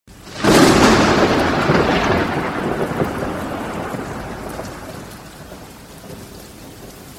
звук молнии